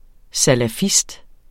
Udtale [ salaˈfisd ]